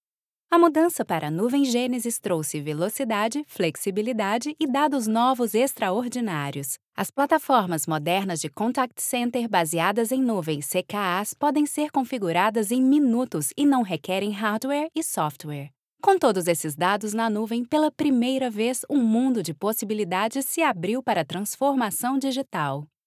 Portugiesisch (Brasilien)
Kommerziell, Junge, Sanft
Erklärvideo